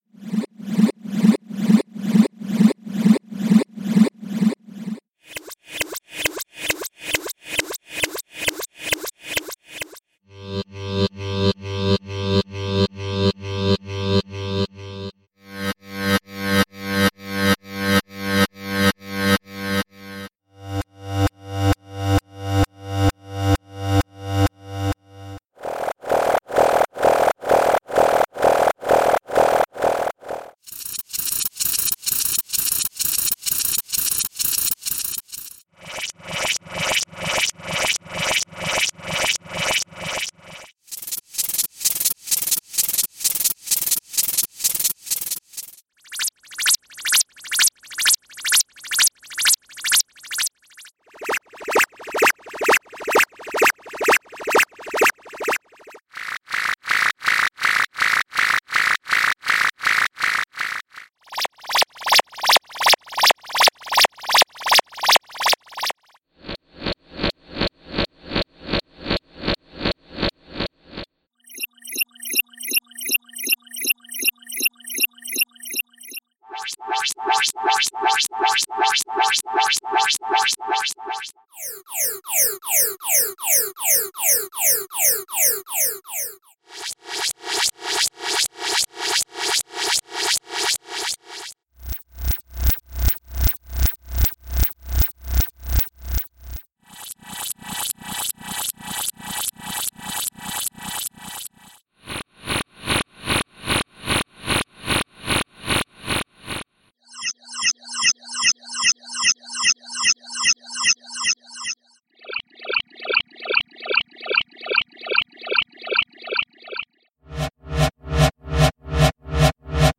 Sound Effects - SIGNALS - V1 - p2
Signals Actual Length: 1 Minute (60 Sec) Each Sound.